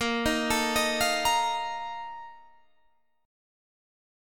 A#M7sus4 Chord